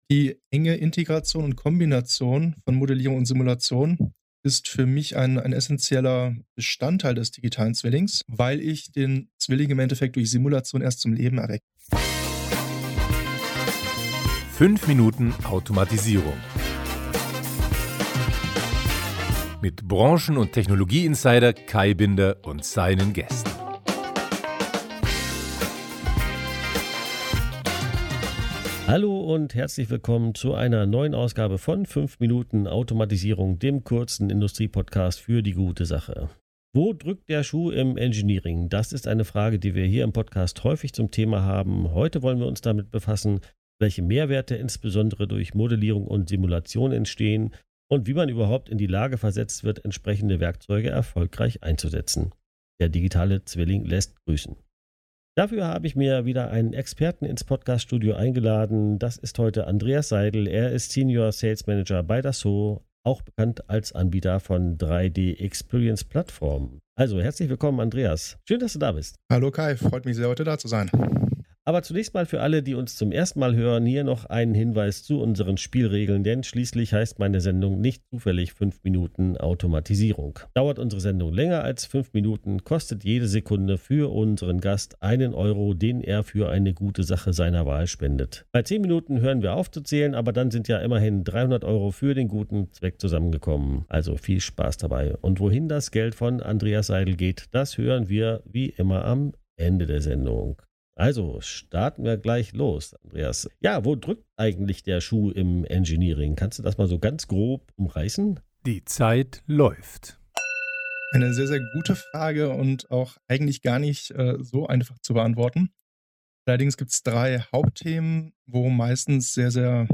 Dieses mal erläutern wir, welche Mehrwerte insbesondere durch Modellierung und Simulation entstehen und wie man überhaupt in die Lage versetzt wird, entsprechende Werkzeuge erfolgreich einzusetzen - der digitale Zwilling lässt grüßen. Dafür habe ich mir wieder einen Experten ins Podcast-Studio eingeladen.